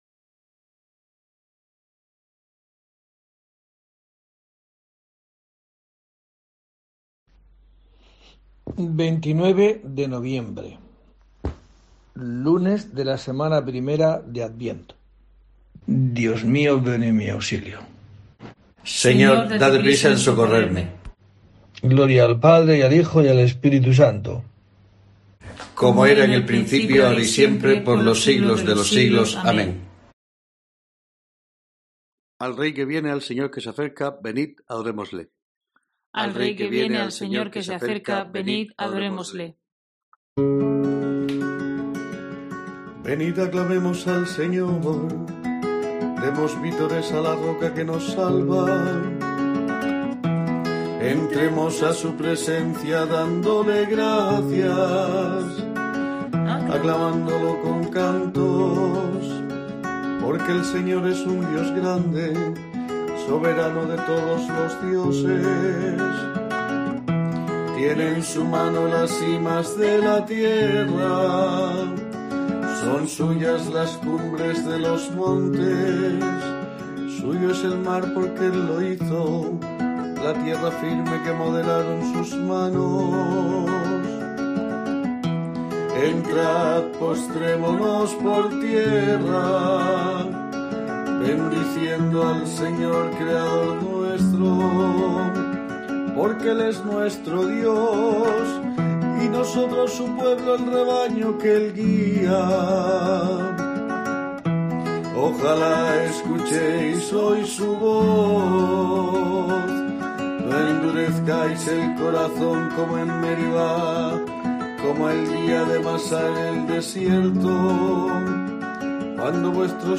29 de noviembre: COPE te trae el rezo diario de los Laudes para acompañarte